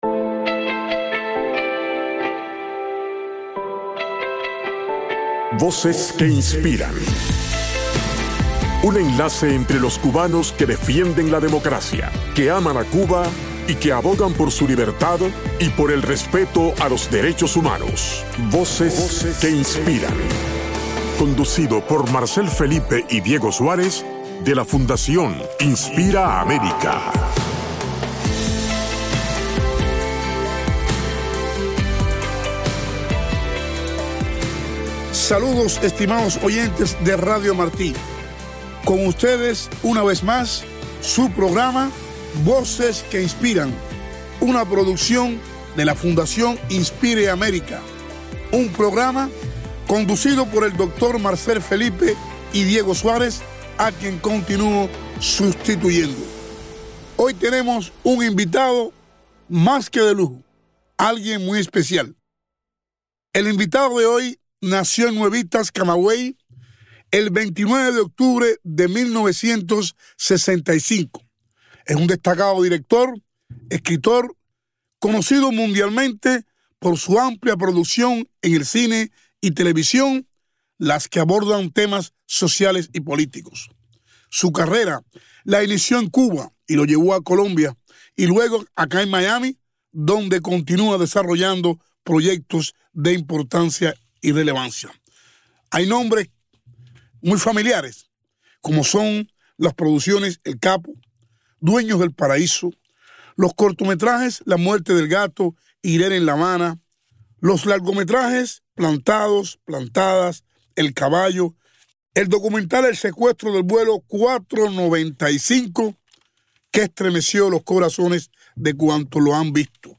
Entrevista a Lilo Vilaplana